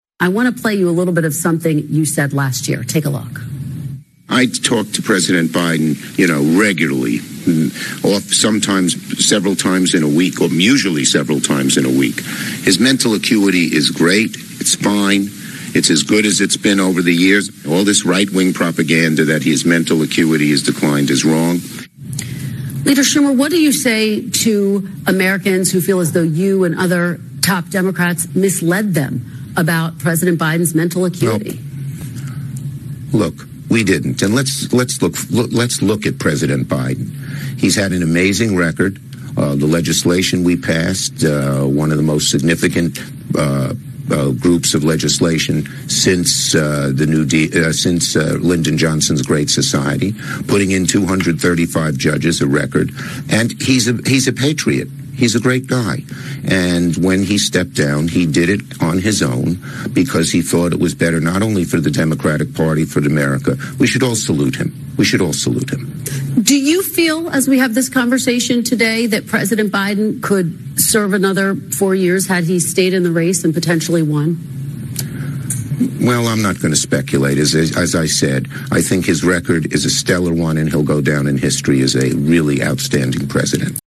Senate Majority Leader Chuck Schumer was called out for dodging Biden’s mental decline. When pressed by NBC’s Kristen Welker, Schumer quickly shifted the conversation.